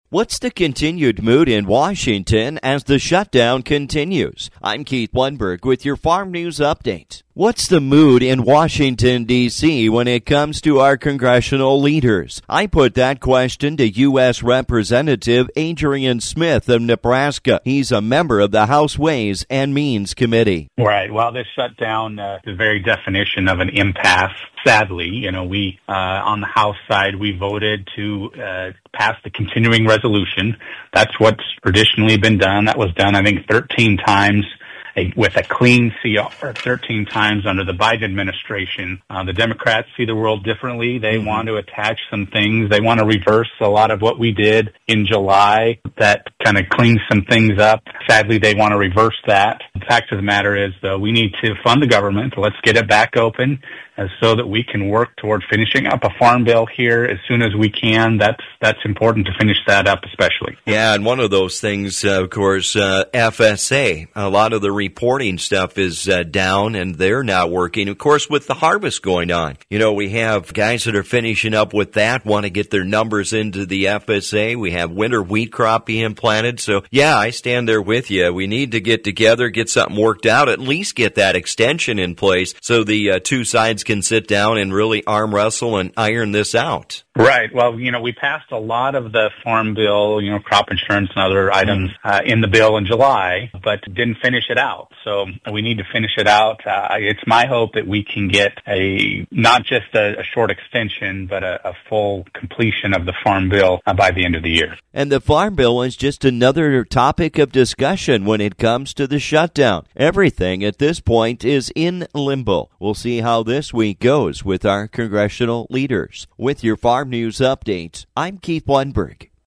As we now go into the 3rd week of the U.S. Government Shut Down, we review the impacts with U.S. Representative Adrian Smith of Nebraska.